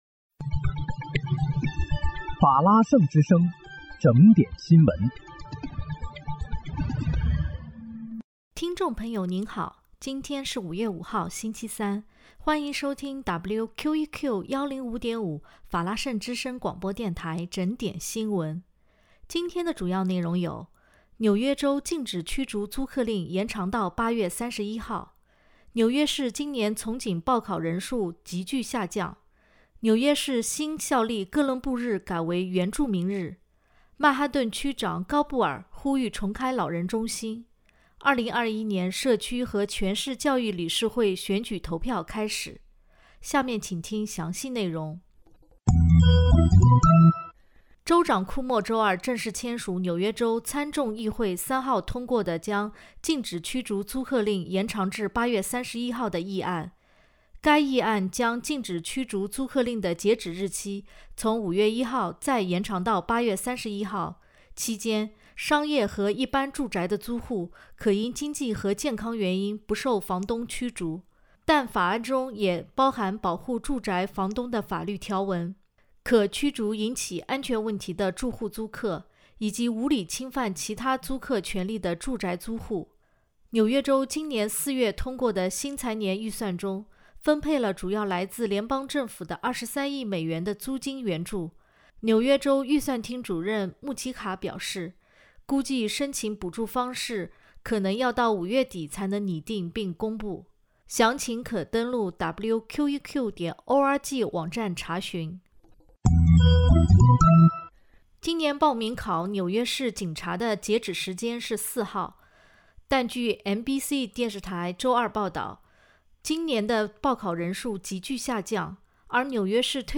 5月5日(星期三）纽约整点新闻